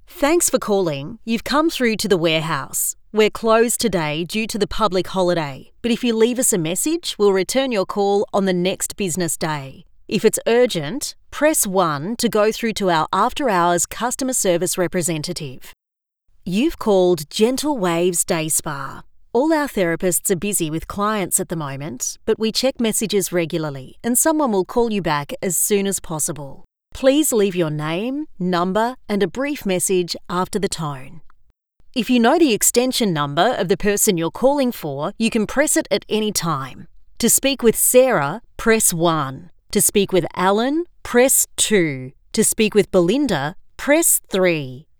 • On Hold
• Natural, warm,
• Mic: Rode NT1-A